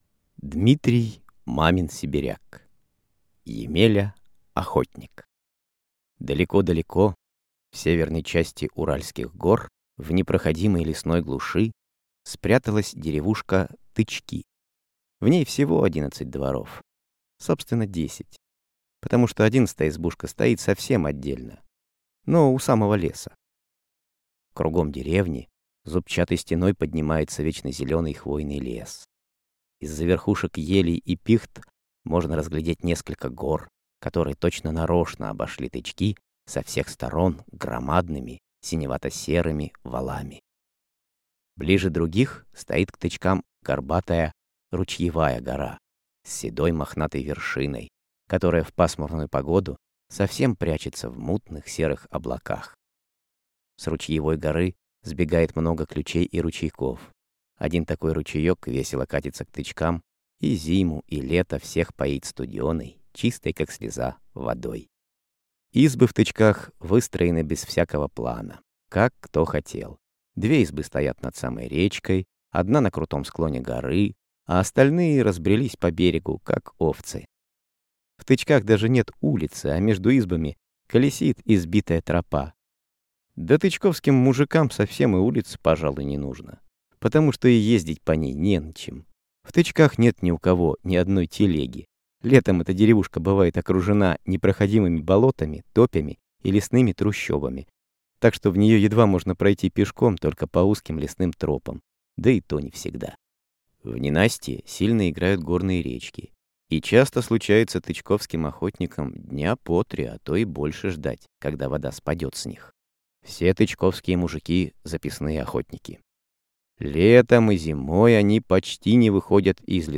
Аудиокнига Емеля-охотник | Библиотека аудиокниг